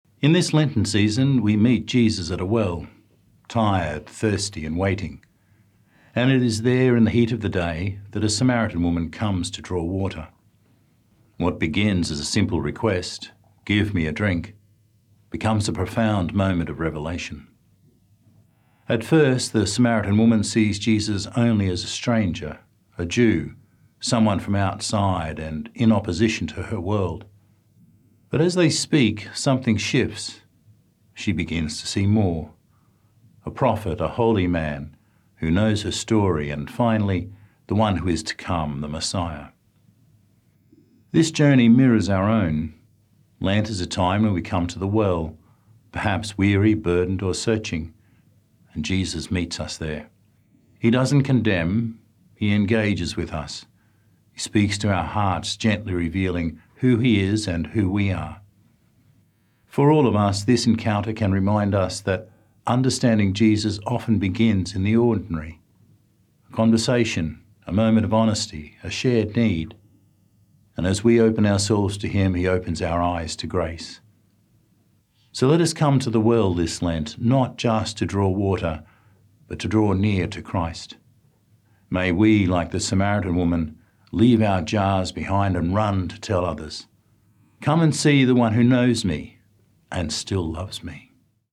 Archdiocese of Brisbane Third Sunday of Lent - Two-Minute Homily